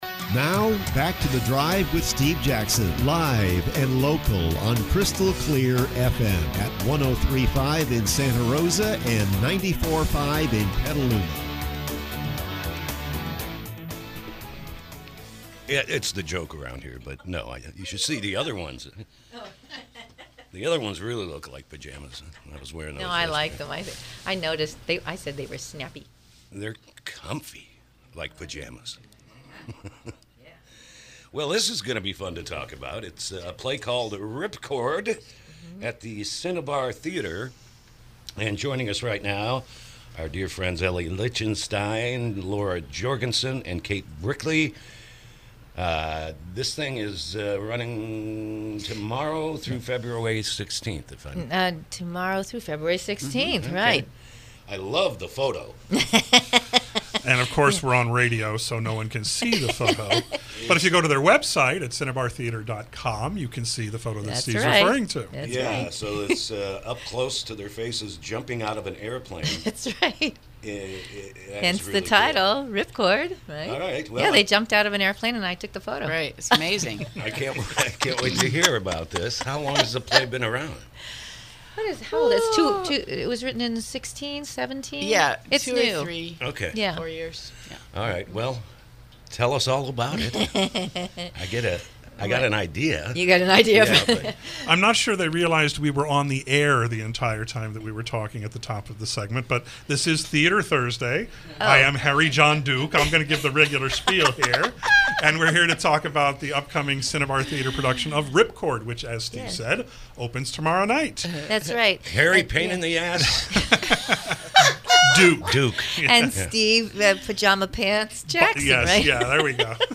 KSRO Interview – “Ripcord”